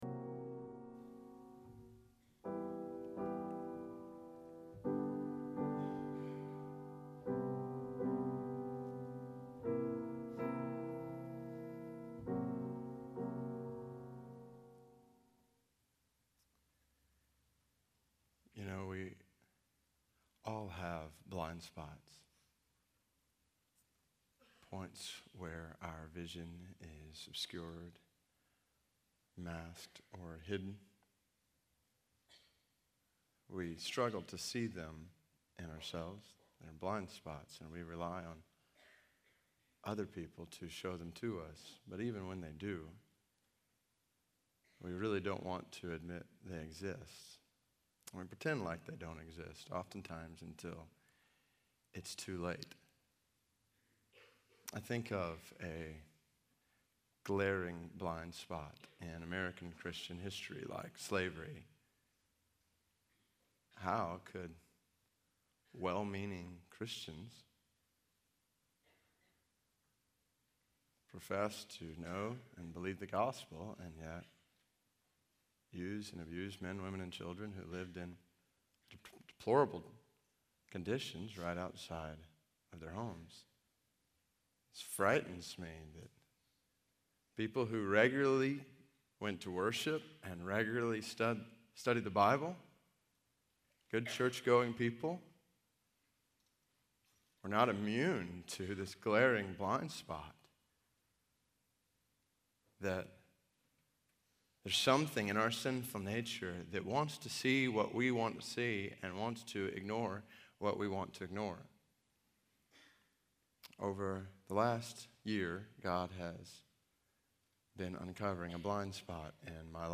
Faith in Practice Chapel: David Platt